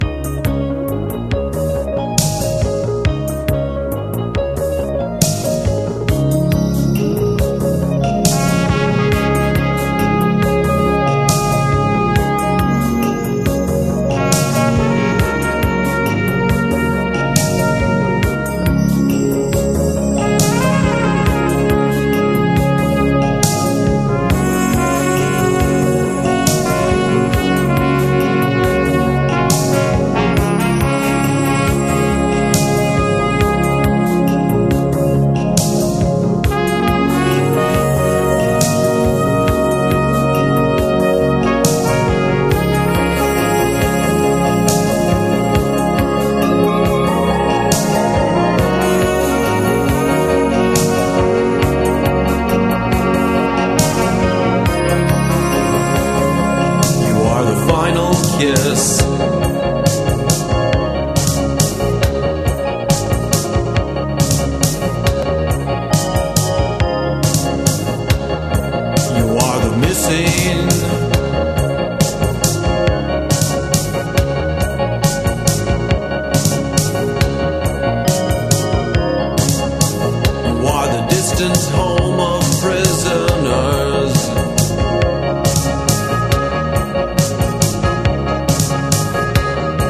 POLKA / WORLD